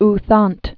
( thänt, thănt)